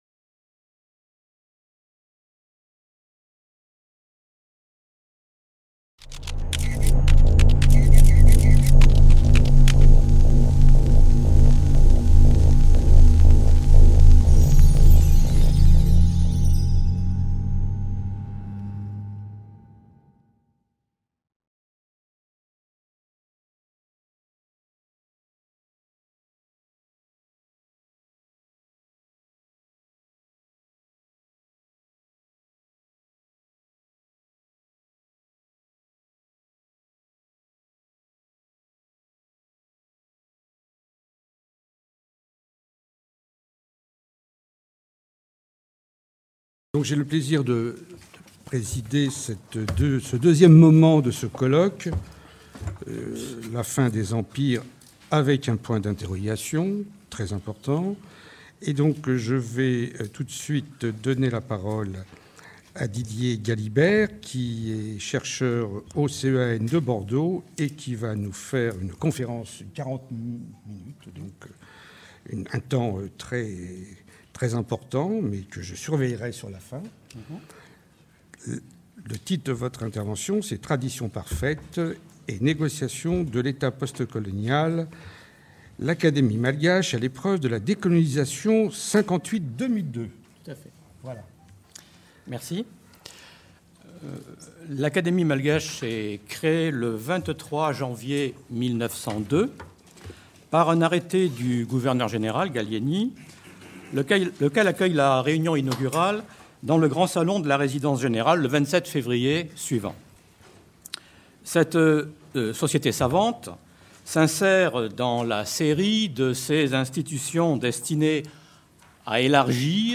Enregistrées les Jeudi 21 octobre 2010 et vendredi 22 octobre 2010. Résumé : Cinquante ans sont passés depuis les indépendances de dix-huit pays de l’Afrique anglophone, francophone et italophone.